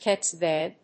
音節CCTV 発音記号・読み方
/ˈsiˌsiˈtiˌvi(米国英語), ˈsi:ˌsi:ˈti:ˌvi:(英国英語)/